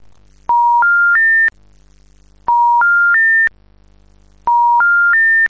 Звуки звонящего телефона
Звук сигнала при неправильно набранном номере на сотовом гудок